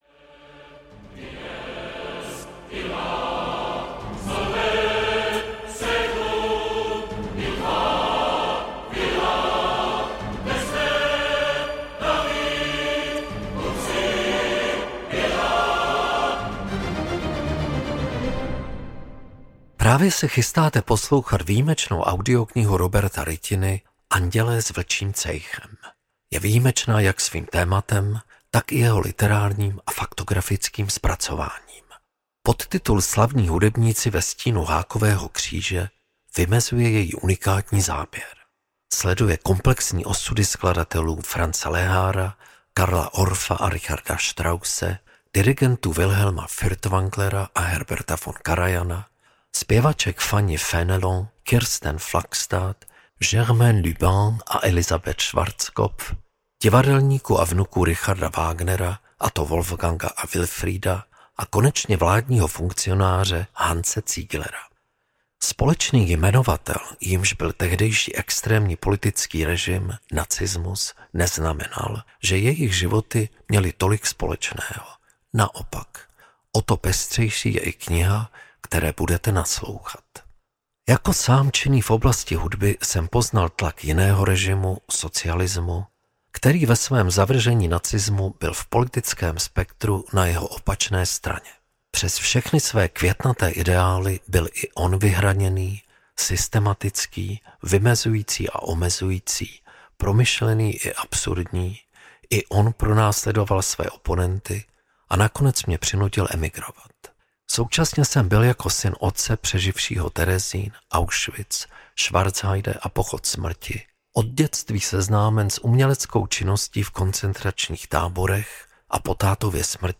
Andělé s vlčím cejchem audiokniha
Ukázka z knihy